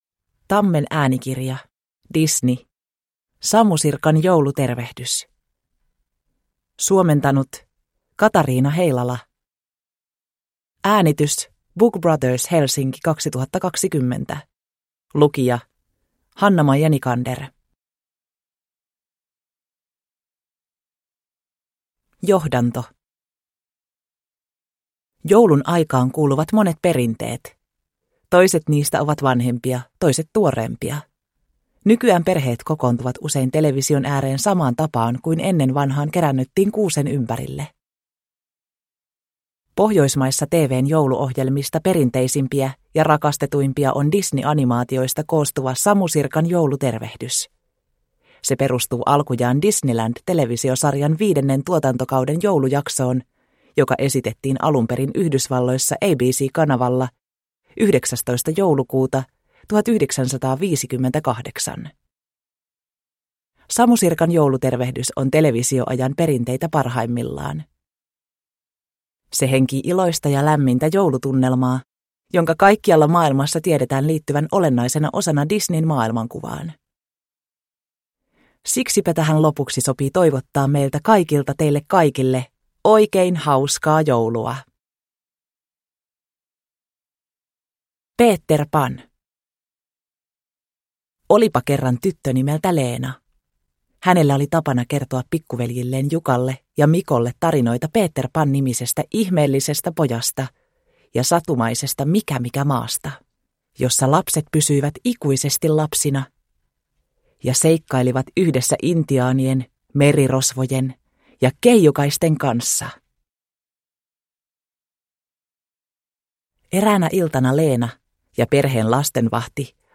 Samu Sirkan joulutervehdys – Ljudbok – Laddas ner